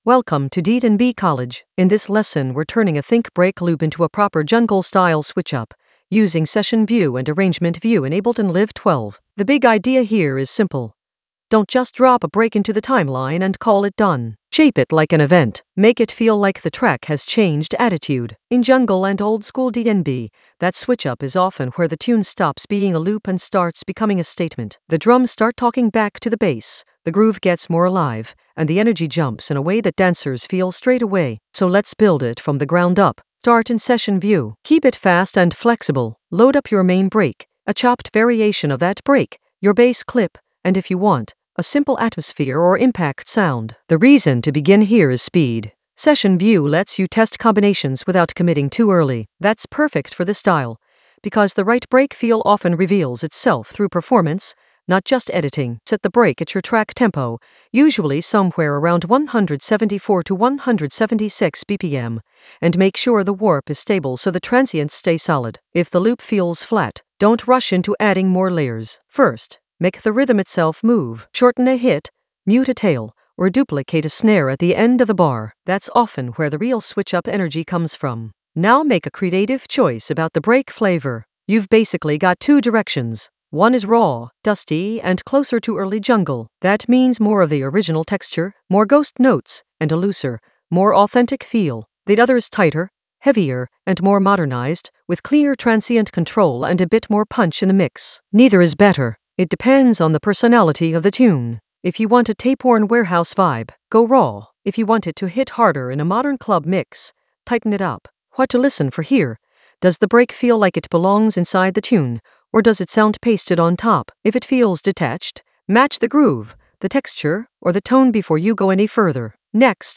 An AI-generated intermediate Ableton lesson focused on Drive a think-break switchup using Session View to Arrangement View in Ableton Live 12 for jungle oldskool DnB vibes in the Drums area of drum and bass production.
Narrated lesson audio
The voice track includes the tutorial plus extra teacher commentary.